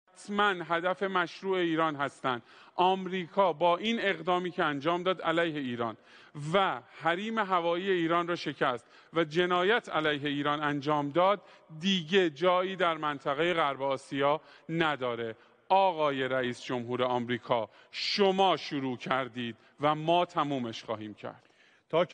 מגיש בטלוויזיה האיראנית עם מפת בסיסי ארה''ב במזרח התיכון